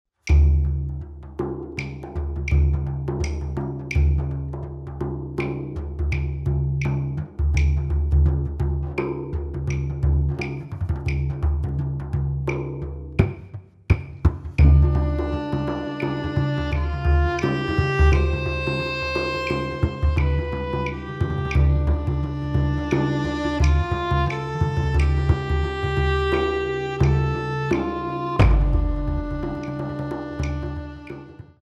bodhran, bass drum
violin
clave